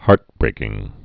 (härtbrākĭng)